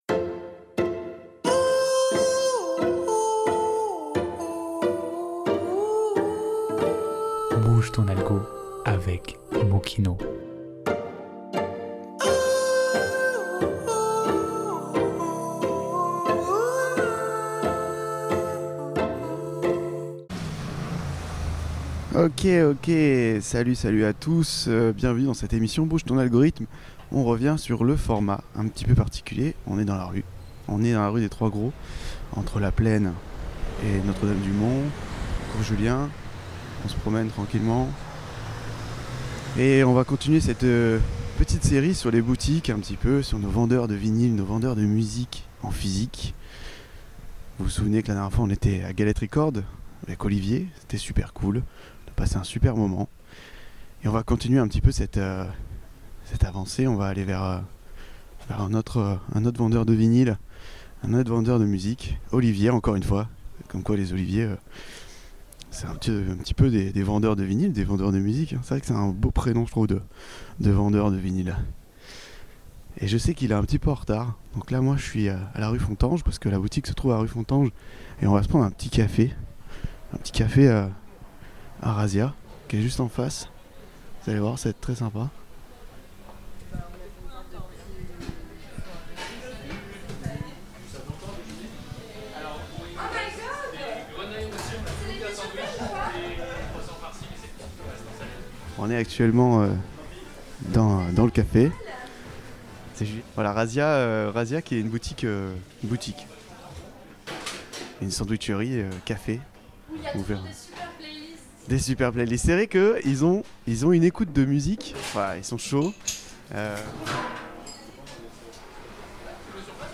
Vendredi 30 Janvier 2026 Émission spéciale en immersion chez nos disquaires marseillais pour découvrir l’envers du décor musical local.